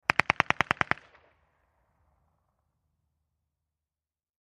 Mac-10 Machine Gun Burst From Distant Point of View, X2